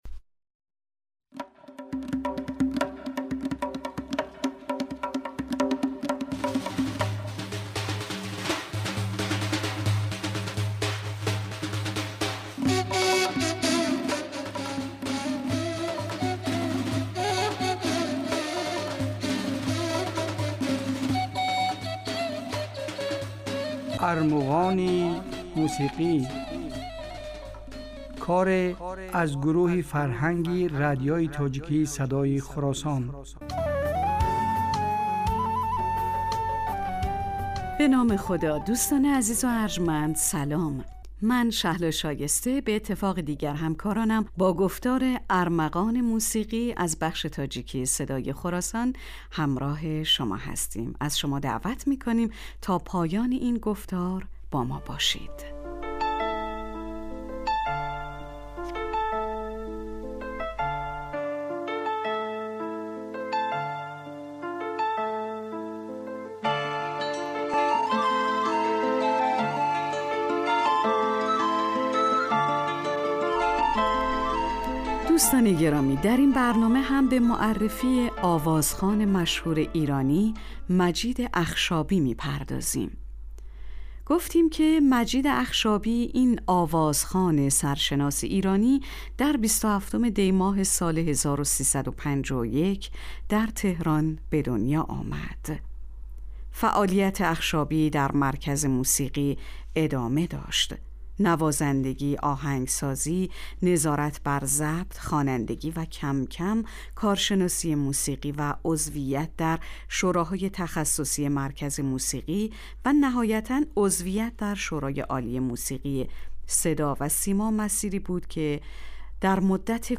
Дар ин барномаҳо кӯшиш мекунем, ки беҳтарин ва зеботарин мусиқии тоҷикӣ ва...